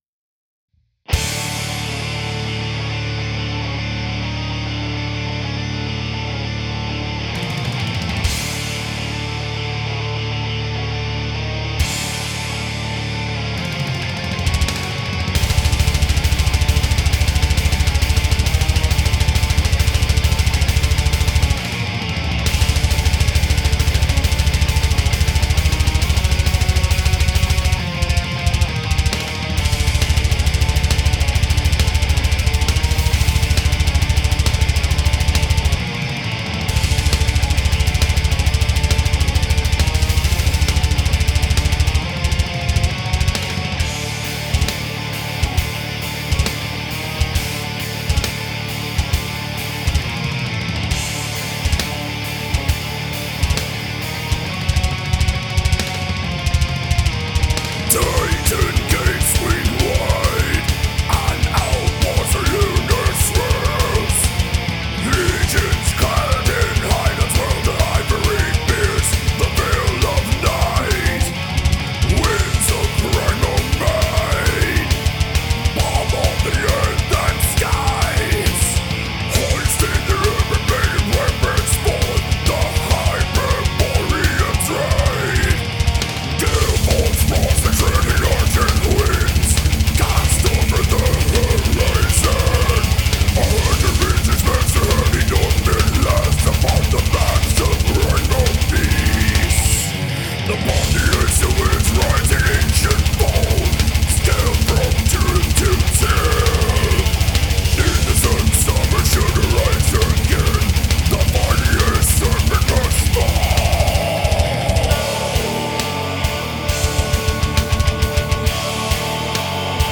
death, black and doom metal band